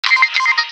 03 Camera.aac